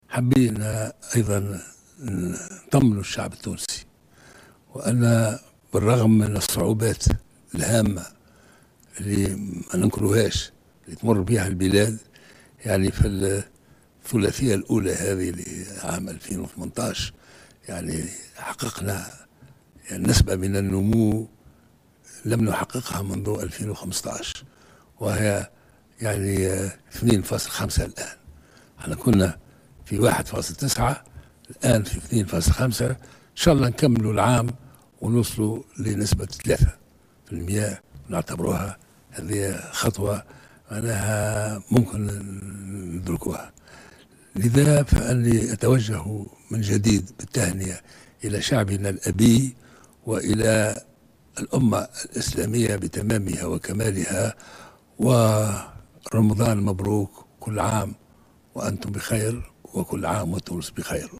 أعرب رئيس الجمهورية الباجي قائد السبسي، في كلمة توجه بها إلى الشعب التونسي، بمناسبة حلول شهر رمضان المعظم مساء اليوم الأربعاء 16 ماي 2018، عن الأمل في بلوغ نسبة نمو تقدر بـ3 بالمائة مع موفى السنة الحالية.